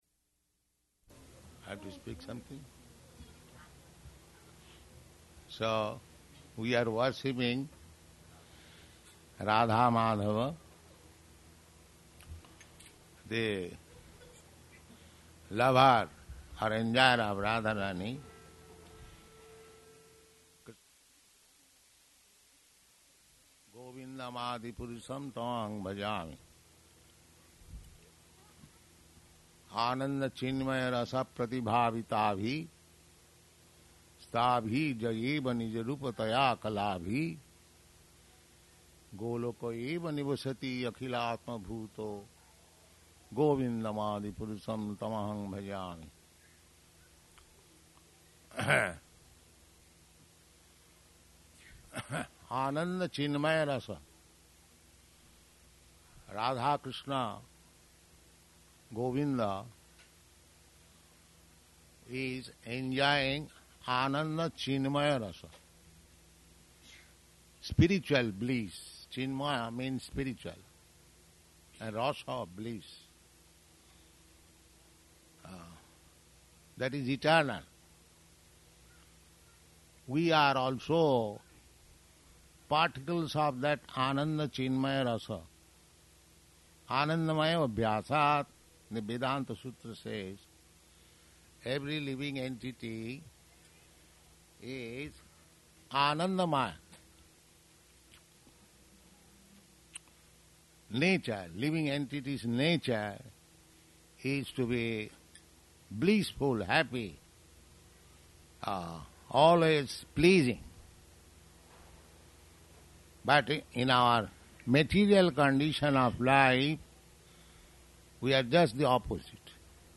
Śrī Brahma-saṁhitā, Verse 37 --:-- --:-- Type: Sri Brahma Samhita Dated: August 11th 1971 Location: London Audio file: 710811BS-LONDON.mp3 Prabhupāda: [aside:] I have to speak something?